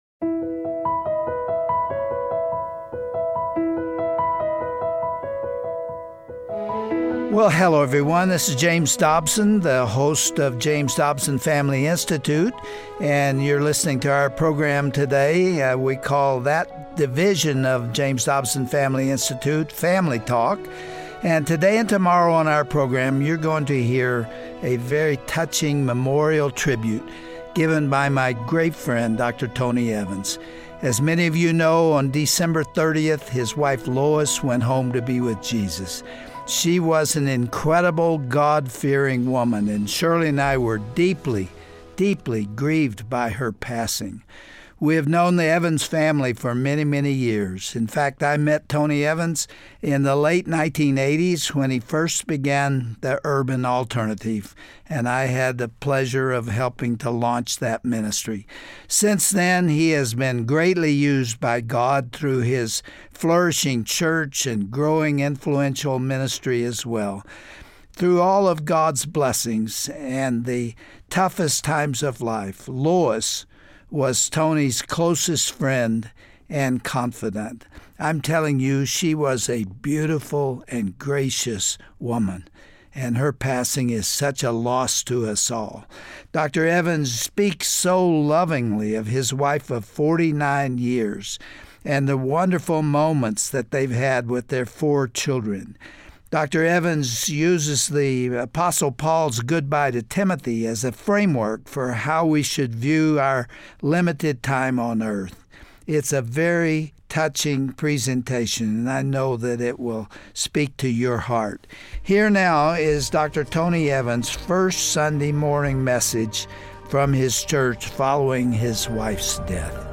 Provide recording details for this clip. He shares heartfelt moments from her last few daysand uses the Apostle Pauls goodbye to Timothy as a basis for our eternal perspective. Hear the biblical way we should honor someones Christ centered legacy on todays broadcast.